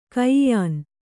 ♪ kaiyān